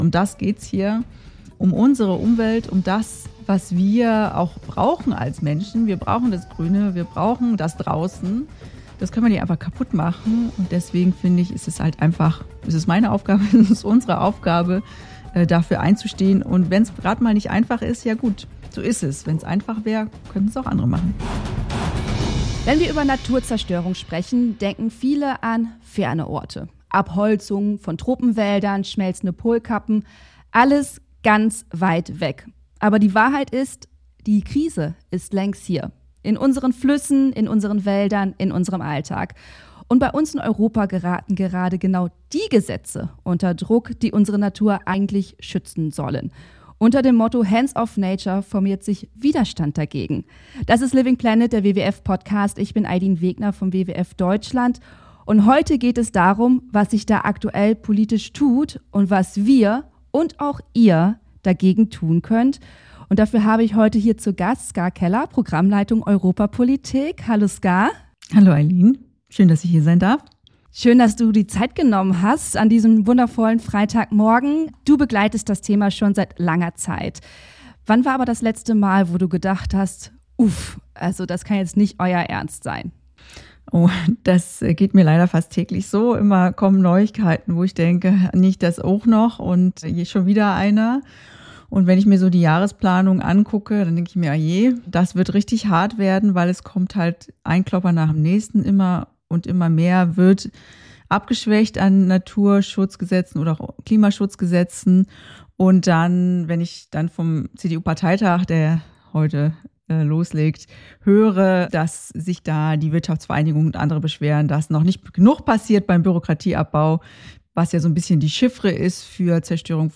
In dieser Folge sprechen wir mit Ska Keller (Programmleitung Europapolitik, WWF Deutschland) über die drohende Aufweichung der Wasserrahmenrichtlinie, die Abschwächung der EU-Waldschutzverordnung und den angekündigten „Stresstest“ der Flora-Fauna-Habitat-Richtlinie (FFH). Was bedeuten die angedachten Gesetzesänderungen der EU für unser Klima und unseren Alltag?